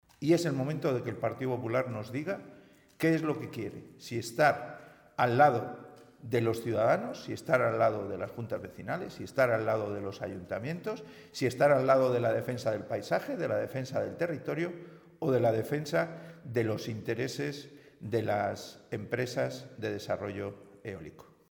Ver declaraciones de Pedro Hernando, portavoz del Grupo Parlamentario Regionalista.